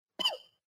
StarrLaser.mp3.ogg